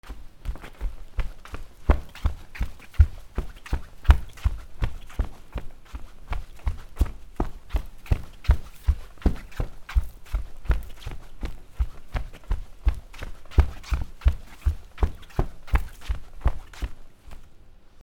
畳の上を走る
/ I｜フォーリー(足音) / I-180 ｜足音 畳
『タッタ』